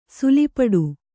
♪ suli paḍu